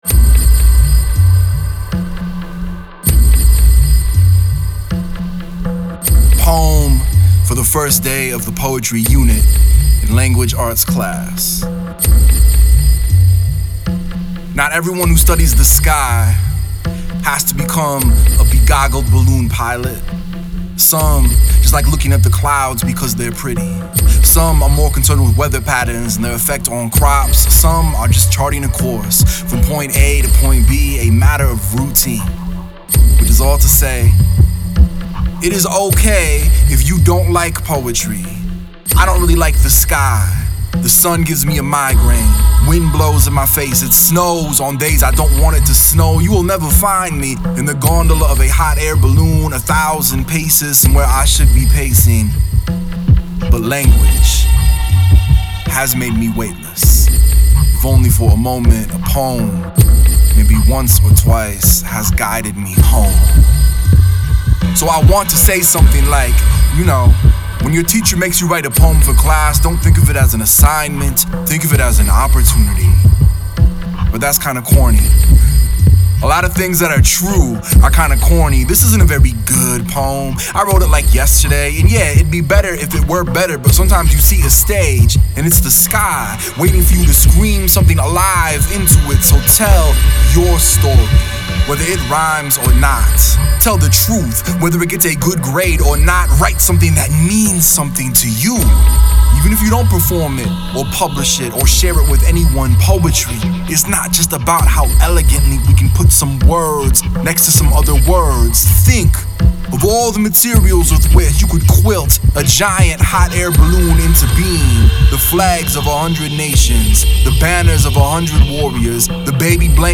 Here is a work-in-progress demo from our upcoming album: